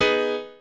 piano8_29.ogg